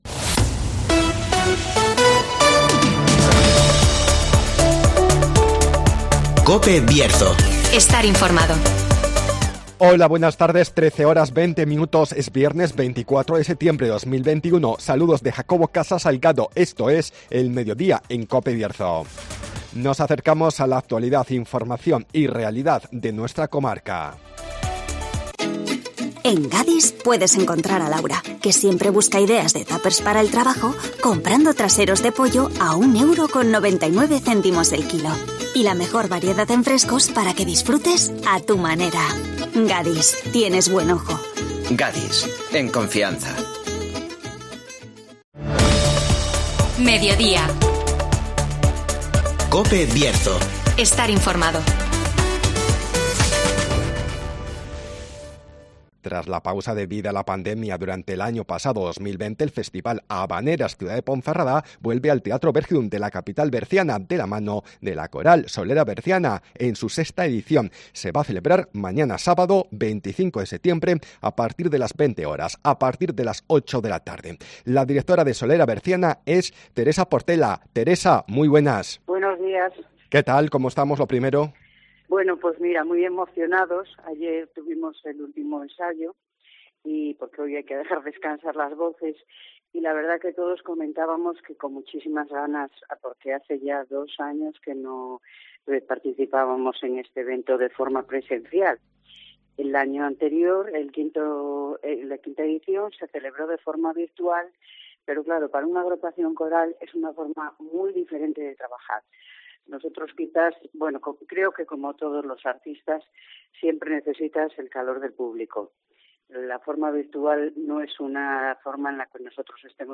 Vuelven las habaneras al Teatro Bergidum de Ponferrada (Entrevista